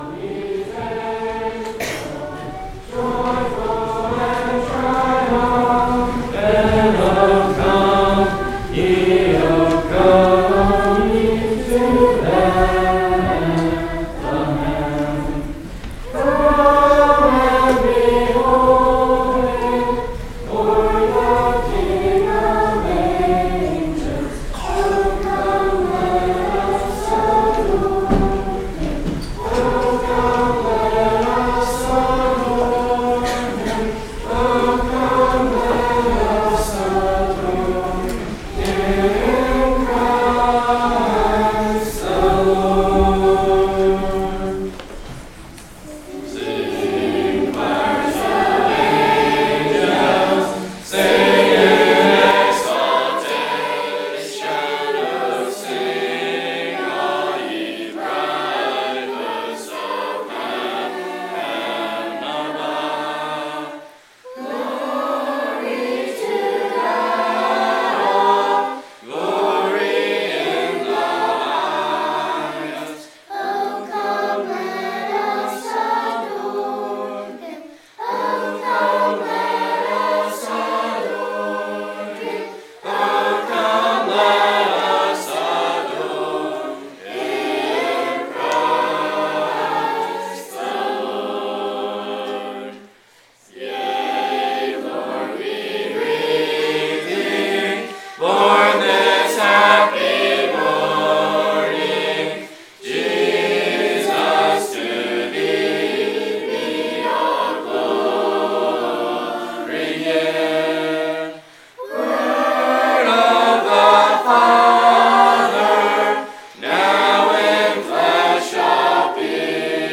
“O Come All Ye Faithful” – Student Body
Service Type: Evening
Grades 5-12 Girls
Congregational Singing